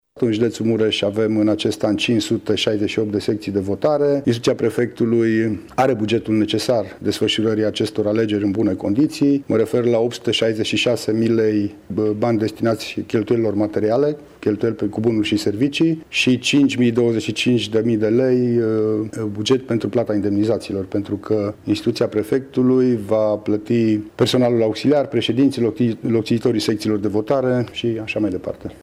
Prefectul judeţului Mureş, Lucian Goga: